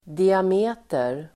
Ladda ner uttalet
diameter substantiv, diameter Uttal: [diam'e:ter (el. ²di'a:-)] Böjningar: diametern, diametrar Definition: rät linje som delar en cirkel i två lika stora delar (a straight line that divides a circle in two equally large parts)